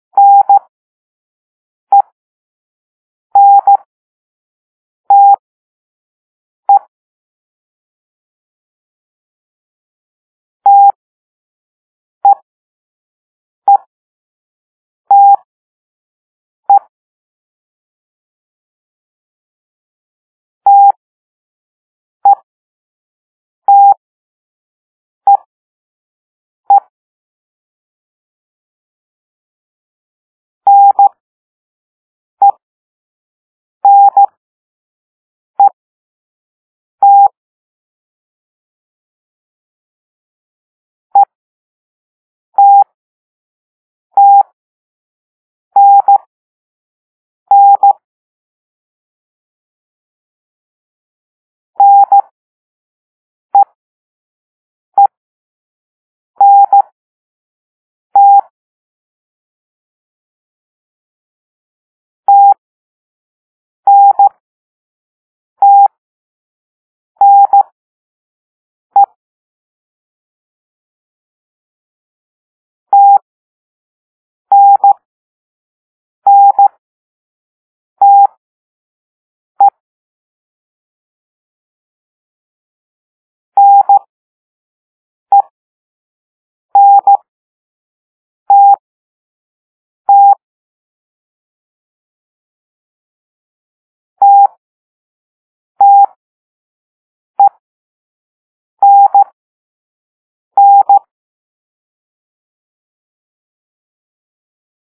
Morse Code Tutorial
It uses the Koch method, which sends the characters at a higher speed while adding delay between characters to bring the overall speed to a slower level.
N - "dah dit"